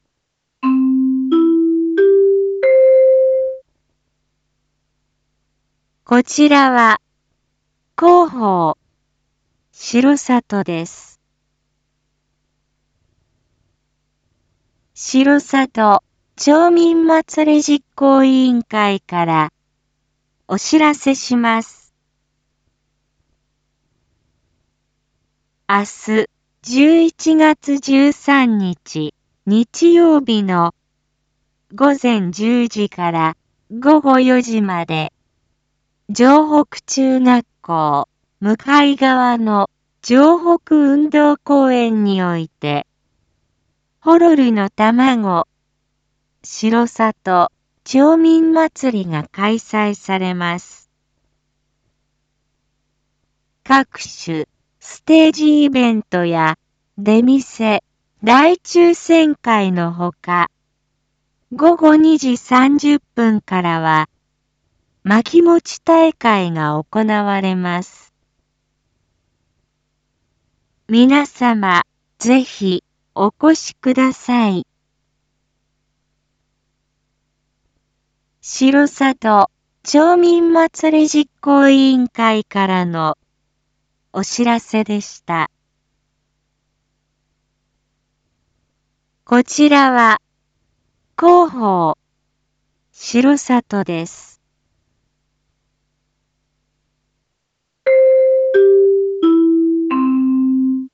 一般放送情報
Back Home 一般放送情報 音声放送 再生 一般放送情報 登録日時：2022-11-12 19:01:42 タイトル：R4.11.12 19時放送分 インフォメーション：こちらは、広報しろさとです。